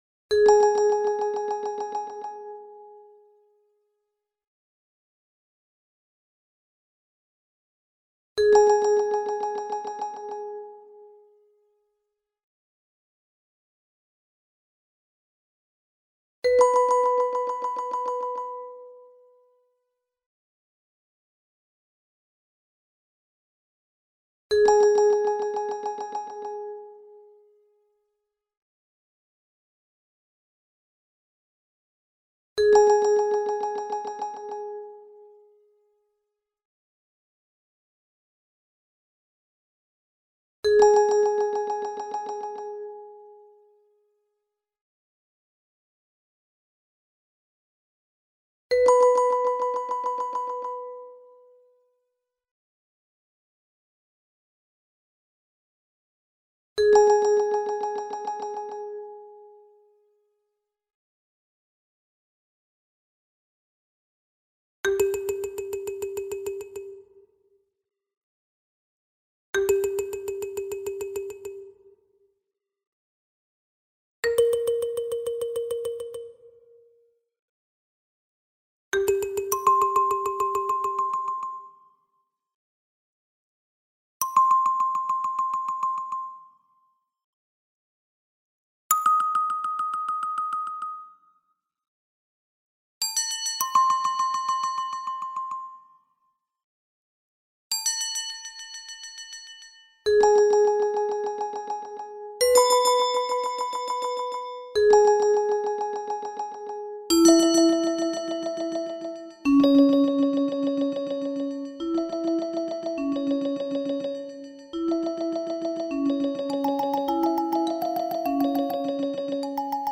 Percussion Ensemble (8-12 players)